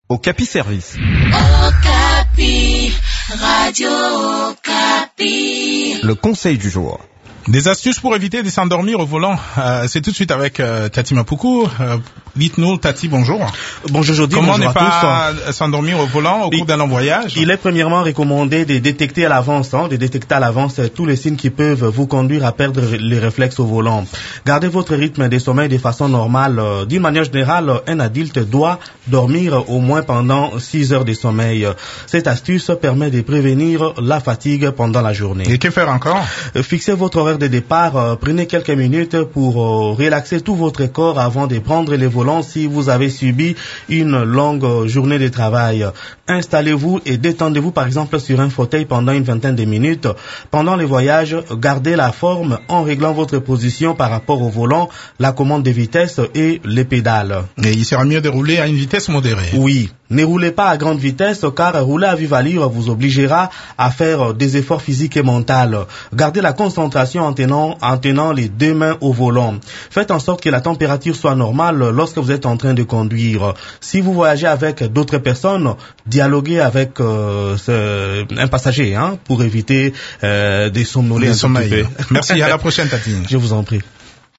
Découvrez des astuces qui peuvent vous aider à  éviter de vous endormir au volant au cours d’un long voyage dans cette chronique